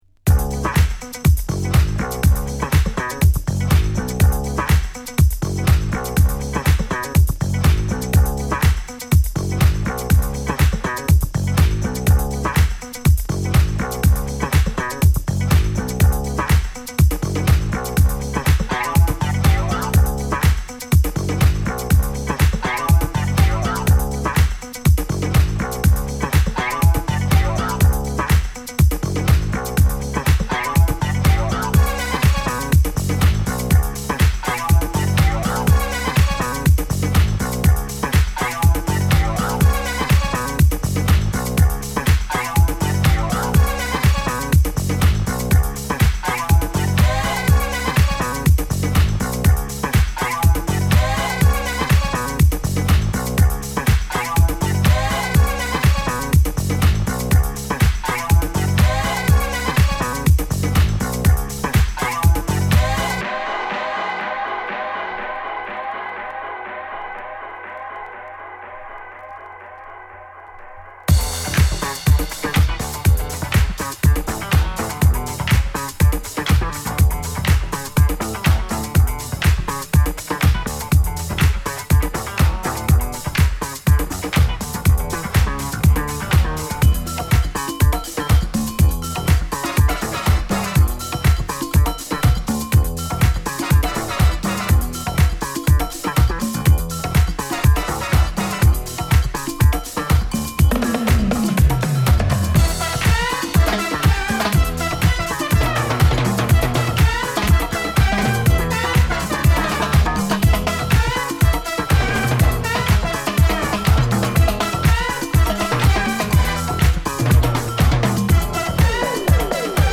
Disco , House
Re-Edit